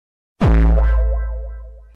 Rizz Bass Boosted Sound Effect Free Download
Rizz Bass Boosted